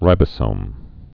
(rībə-sōm)